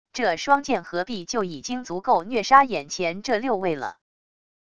这双剑合璧就已经足够虐杀眼前这六位了……wav音频生成系统WAV Audio Player